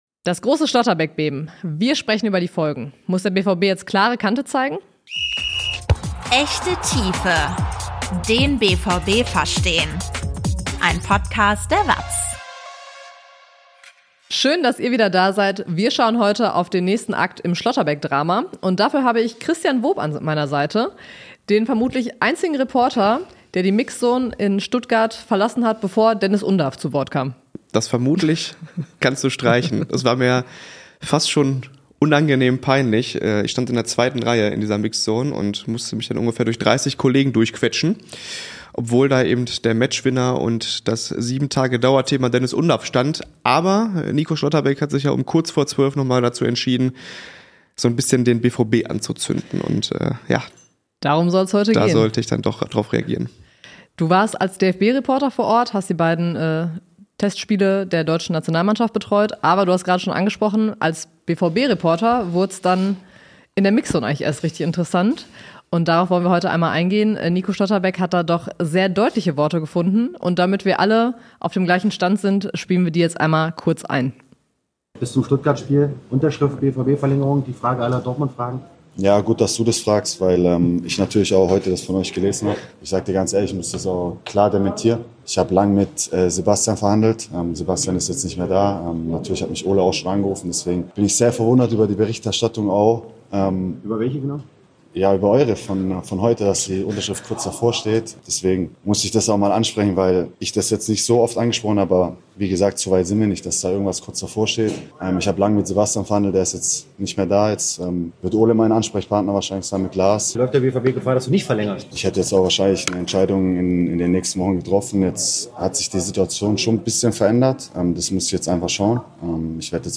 BVB-Talk
BVB-Reporter diskutieren ein Thema in der Tiefe